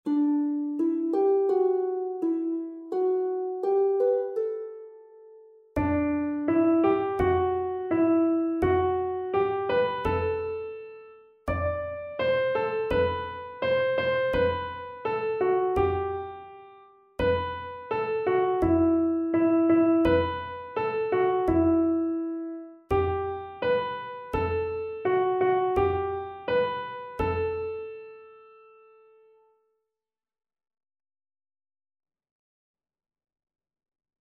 Koor zingt 'Zie hoe hij gaat'